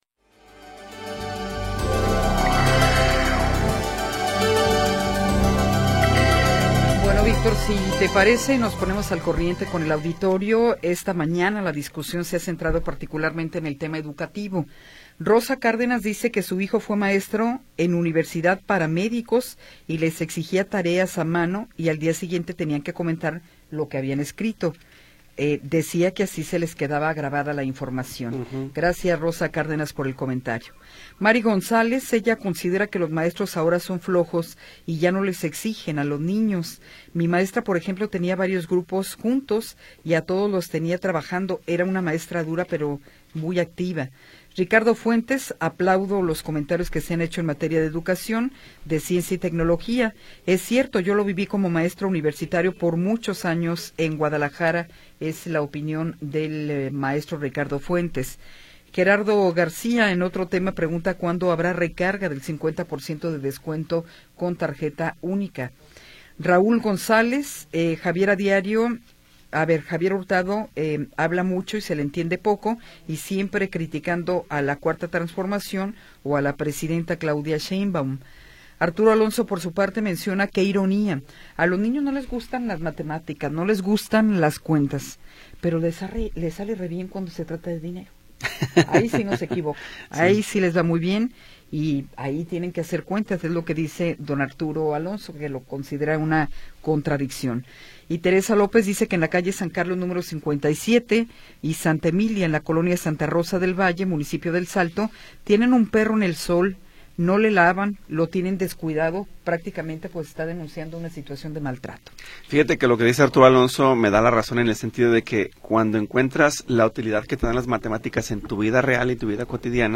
Tercera hora del programa transmitido el 31 de Marzo de 2026.